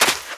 STEPS Sand, Run 02.wav